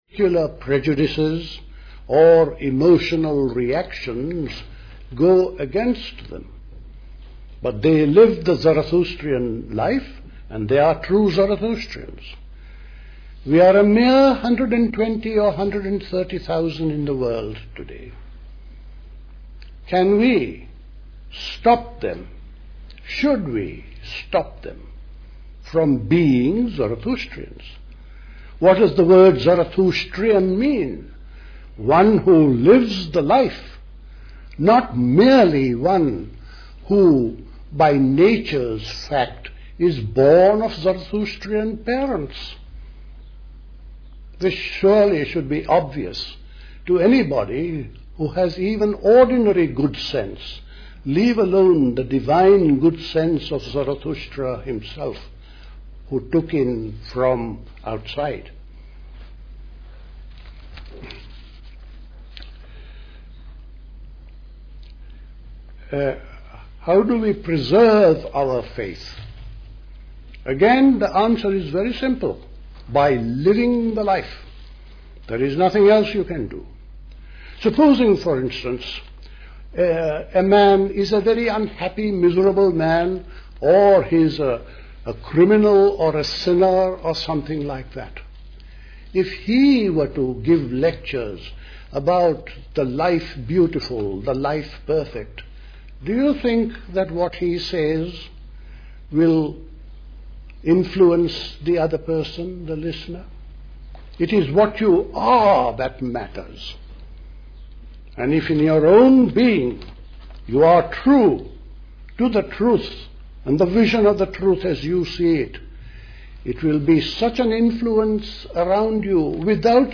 A talk
at Zoroastrian House, Camden, London